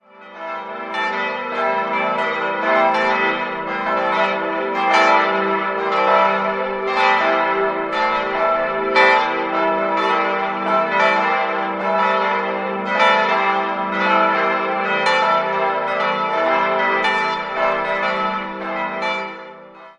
Jahrhundert vor. 4-stimmiges Geläute: e'-g'-a'-h' Die Glocken wurden 1959 von Friedrich Wilhelm Schilling in Heidelberg gegossen.